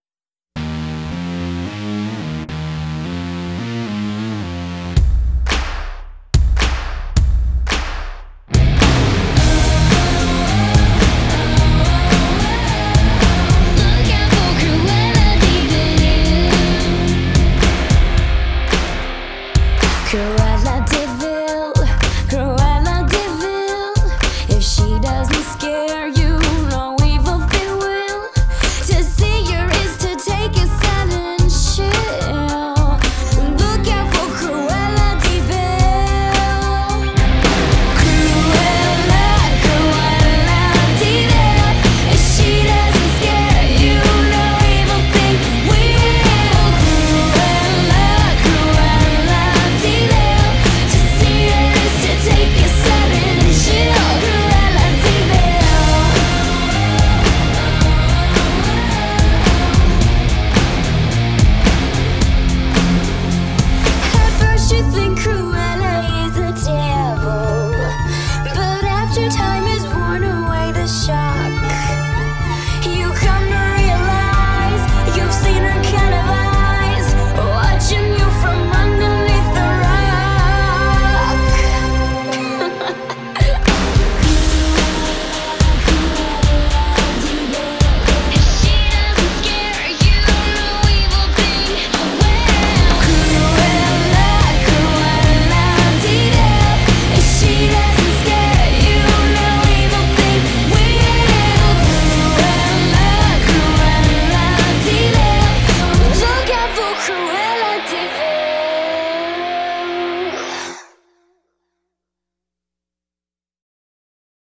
CRUELLA LATIN SHOWDANCE